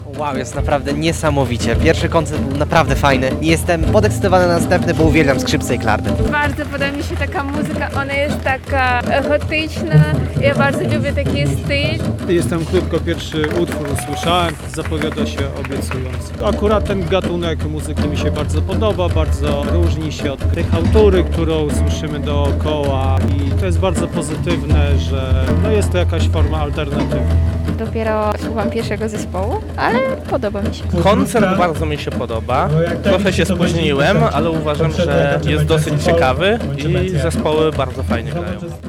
O wrażenia związane z koncertem zapytaliśmy słuchaczy: